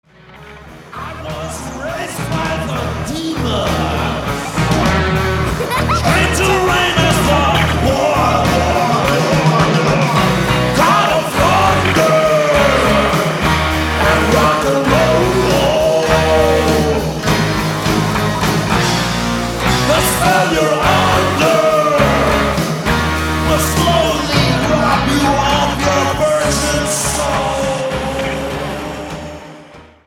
Genre:Heavy Metal
Children Chanting on Song